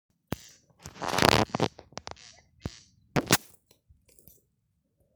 Grieze, Crex crex
StatussDzied ligzdošanai piemērotā biotopā (D)
PiezīmesDzied Skandīnes kapu teritorijā.
Balss likās skan no nopļautā gabaliņa.